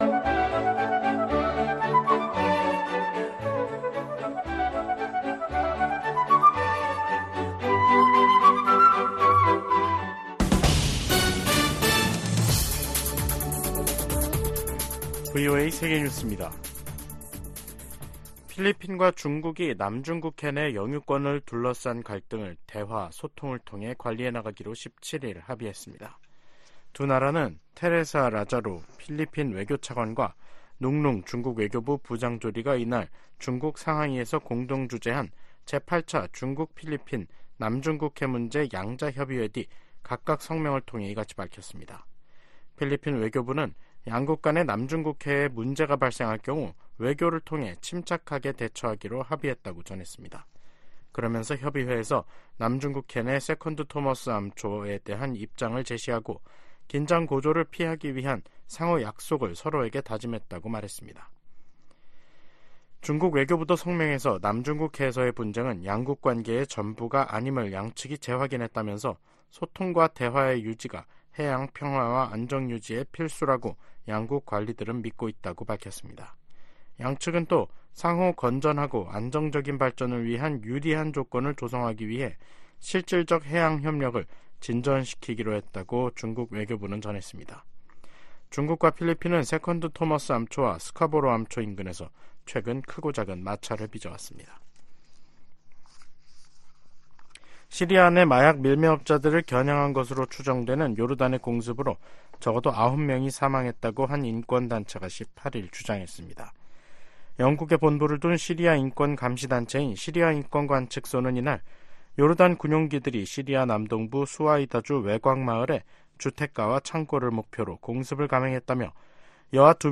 VOA 한국어 간판 뉴스 프로그램 '뉴스 투데이', 2024년 1월 18일 3부 방송입니다. 미국은 북한과 러시아의 무기 거래는 안보리 결의 위반이라고 비판하고, 북한 지도부에 외교에 복귀하라고 촉구했습니다. 미한일 북 핵 수석대표들이 북한에 긴장을 고조시키는 언행과 도발, 무모한 핵과 미사일 개발을 중단할 것을 요구했습니다. 국제 기독교선교단체 '오픈도어스'가 2024 세계 기독교 감시 보고서에서 북한을 기독교 박해가 가장 극심한 나라로 지목했습니다.